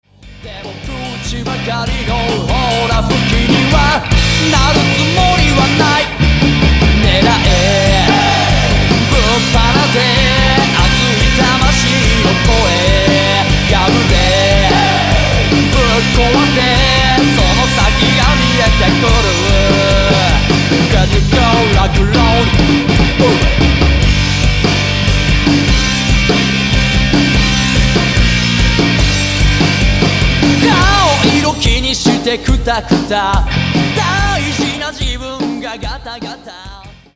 エンターテイメント・パーティ・ハードロックスターバンド!!